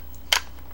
Weapon Attachment Enable.wav